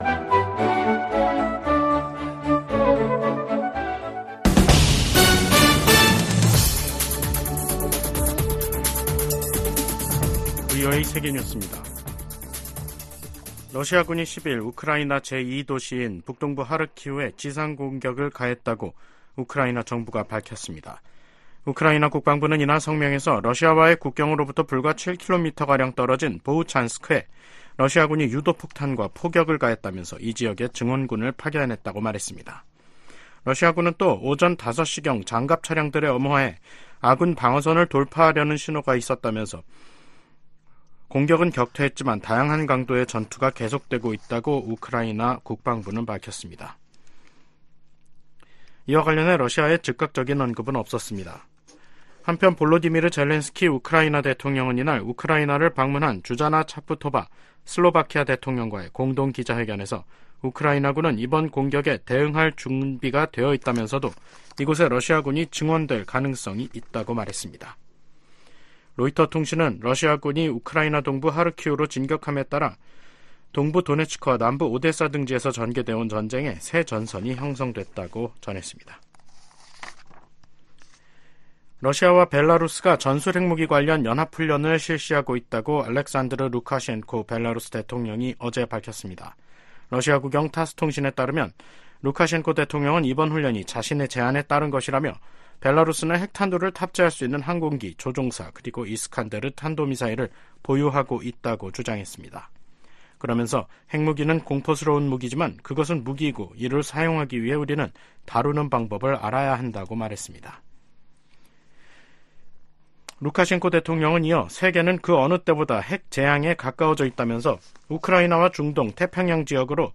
VOA 한국어 간판 뉴스 프로그램 '뉴스 투데이', 2024년 5월 10일 3부 방송입니다. 북한 가상화폐 계좌에 대한 몰수 소송을 담당한 워싱턴 DC 연방법원이 해당 계좌 270여개에 대한 몰수를 명령했습니다. 중국이 최근 탈북민 60여 명을 강제 북송한 것으로 알려진 가운데 미 국무부 북한인권특사가 이에 대한 심각한 우려를 표시했습니다.